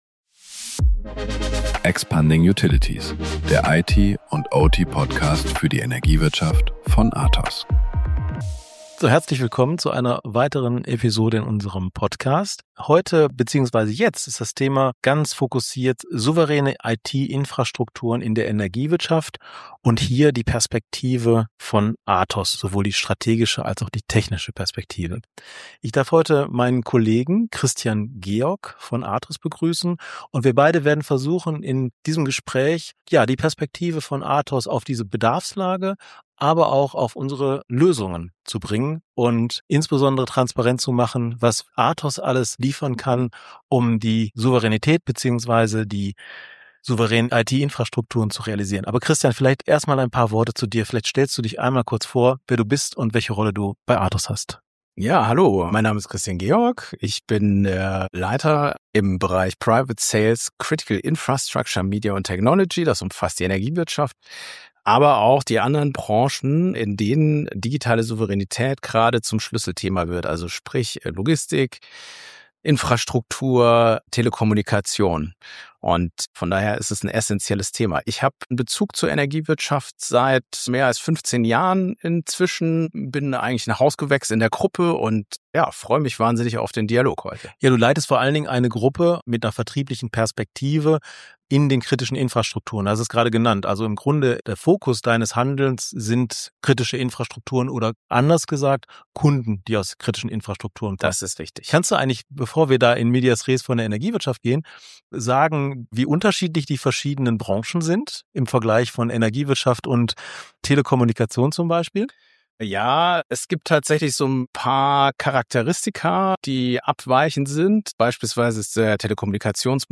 Freut euch auf ein informatives Gespräch voller Praxisbeispiele, strategischer Empfehlungen und spannender Einblicke vom Messegeschehen!